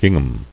[ging·ham]